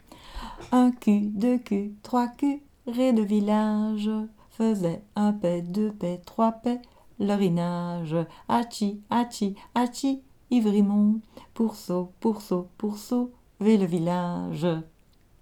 Chanson vulgaire d'humour.
Genre : chant Type : chanson narrative ou de divertissement Thématiques
Lieu d'enregistrement : Malmedy Date d'enregistrement